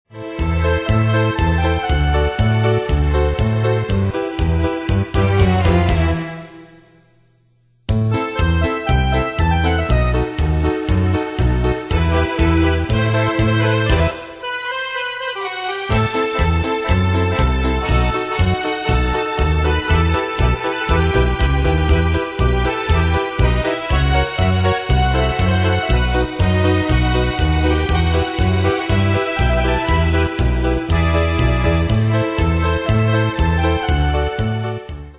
Style: PopFranco Ane/Year: 1995 Tempo: 120 Durée/Time: 2.57
Danse/Dance: Tango Cat Id.
Pro Backing Tracks